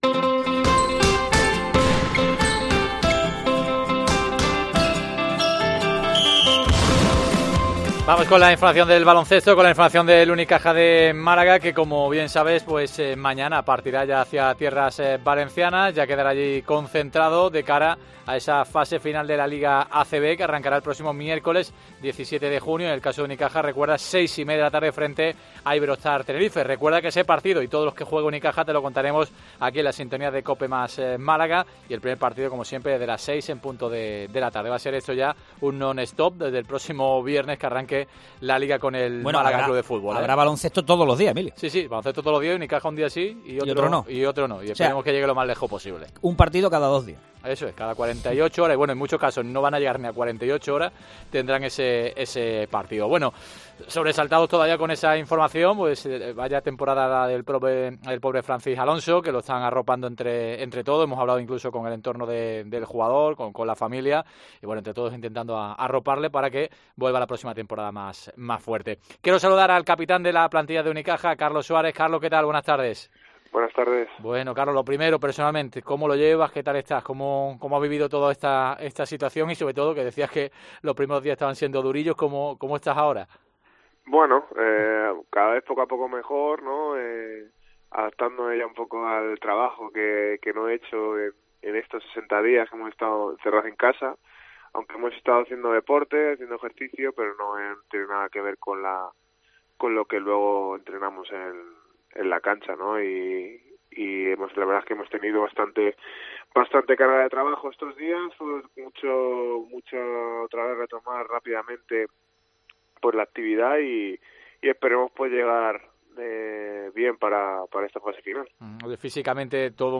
El capitán de Unicaja Carlos Suárez , reflexiona en los micrófonos de "Deportes COPE Málaga" de como llega el equipo malagueño y como han vivido este periodo de confinamiento.